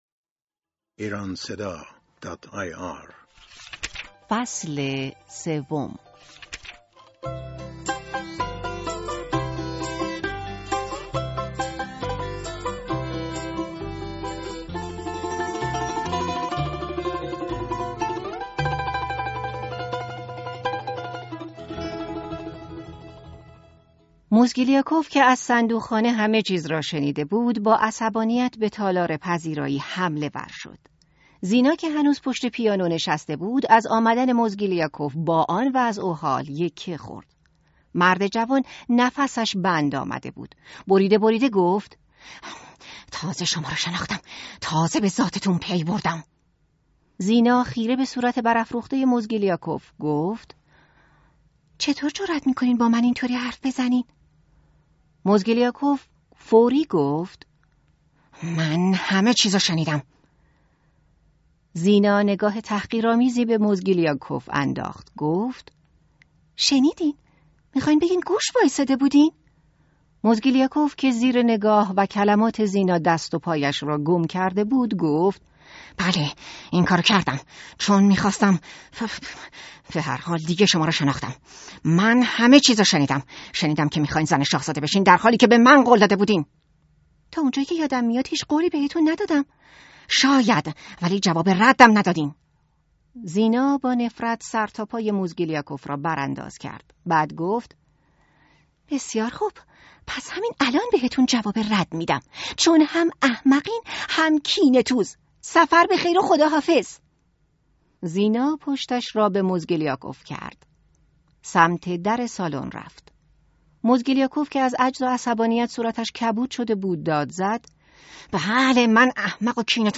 کتاب و نمایش صوتی داستانهای مشهور Listen & Relax Audio Books رویای عمو جان : فئودور داستایفسکی : بخش سوم 2024-04-27 Download Likes Share «ماریا الکساندرونا» زنی جاه‌طلب،حریص و خیلی زیرک است.